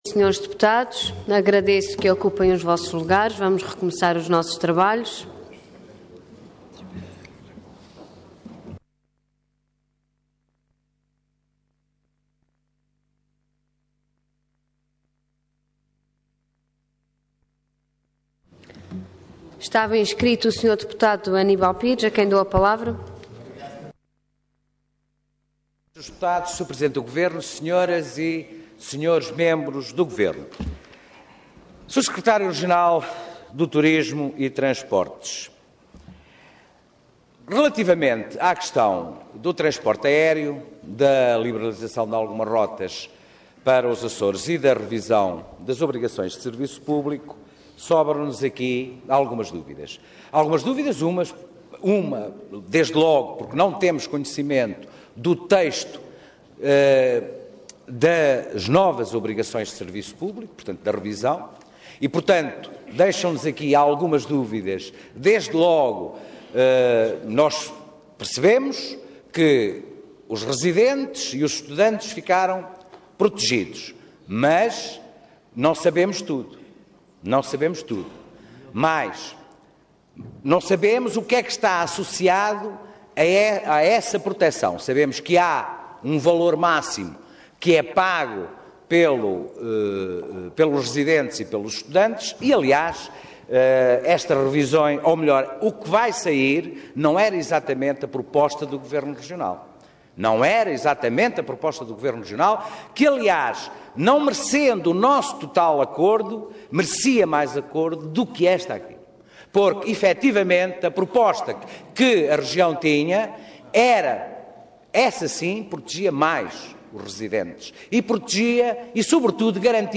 Detalhe de vídeo 26 de novembro de 2014 Download áudio Download vídeo X Legislatura Plano e Orçamento para 2015 - Turismo e Transportes - Parte II Intervenção Proposta de Decreto Leg. Orador Vítor Ângelo de Fraga Cargo Secretário Regional do Turismo e Transportes Entidade Governo